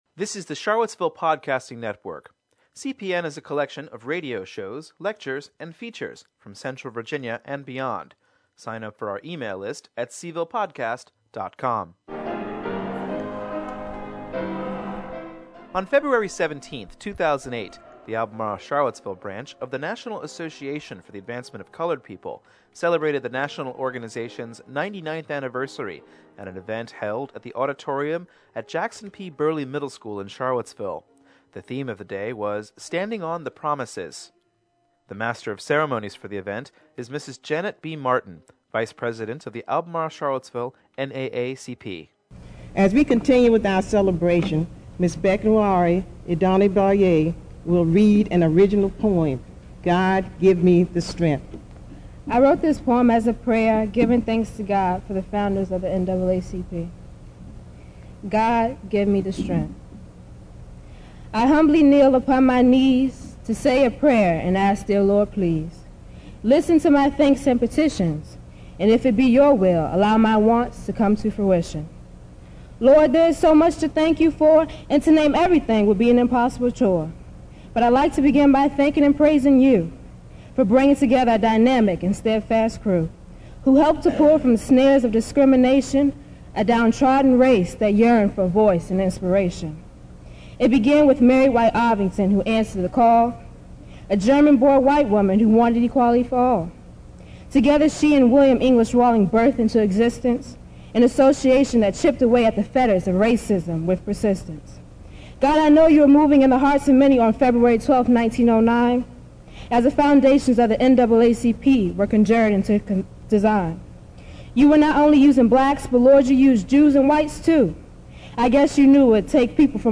We recorded the event, and now bring you the highlights.